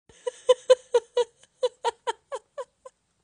Risada